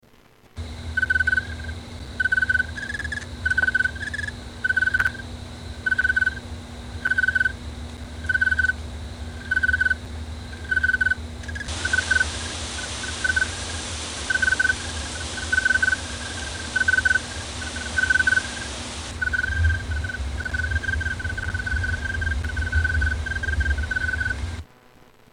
Crickets Sound on Cape Cod 3